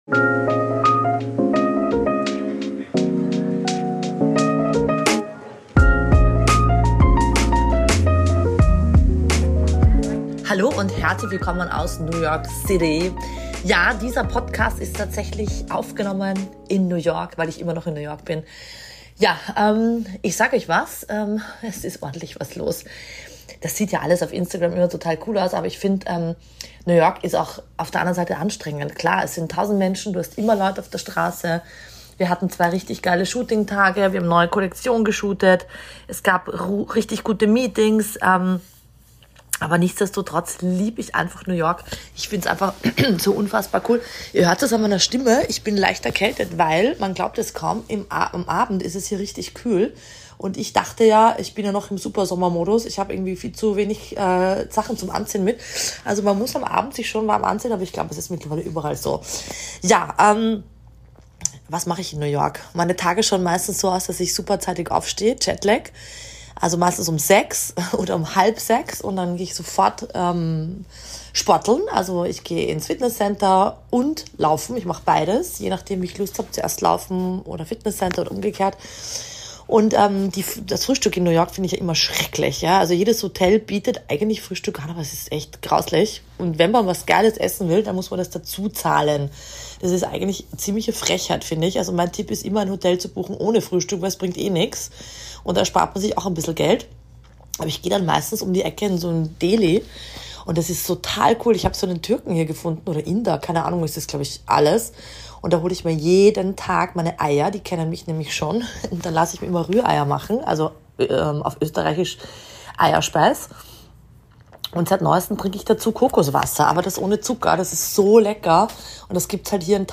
#78 - Live aus New York - sowas Peinliches ist mir hier noch nie passiert!